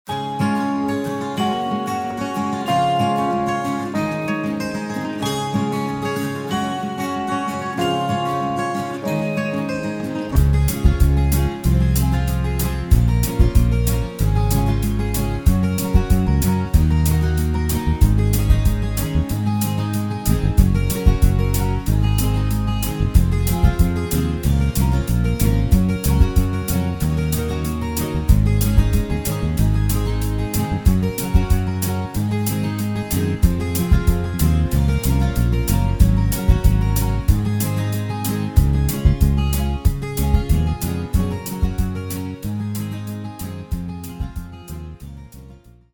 sans choeurs